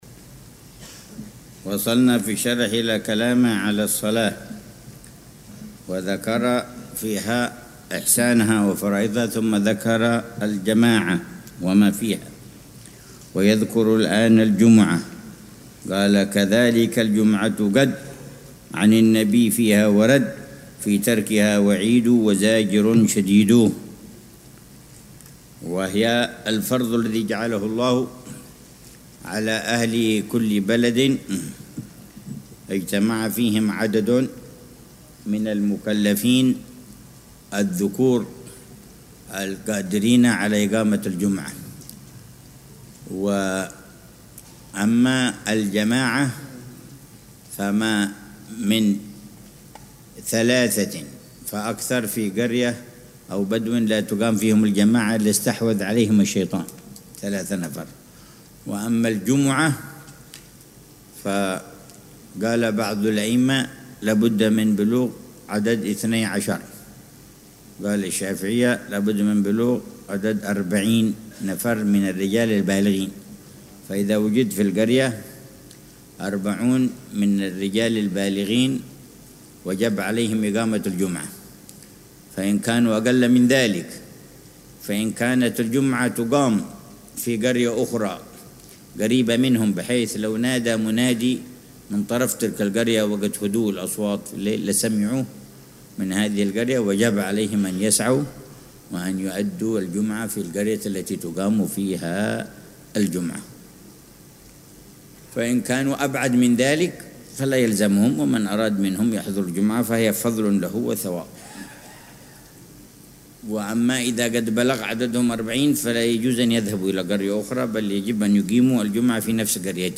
الدرس العشرون ( 9 صفر 1447هـ)